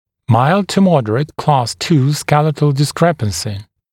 [maɪld-tə-‘mɔdərət klɑːs tuː ‘skelɪtl dɪs’krepənsɪ][майлд-ту-‘модэрэт кла:с ту: ‘скэлитл дис’крэпэнси]умеренно выраженные изменения лицевого отдела черепа при соотношении челюстей по II классу